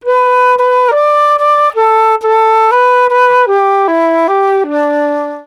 flute.aif